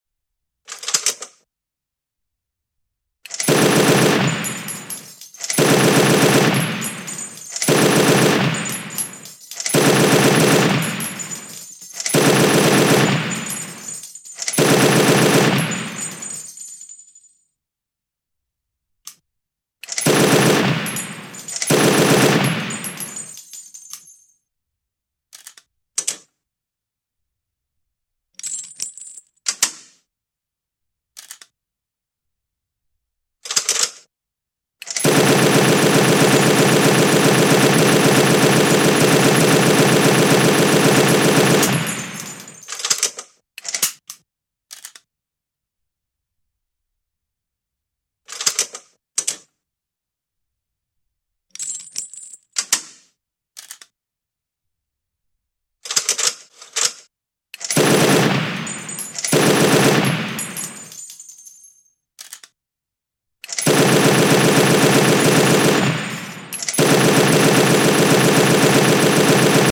US M240L 🏴‍☠ Sound Effects Free Download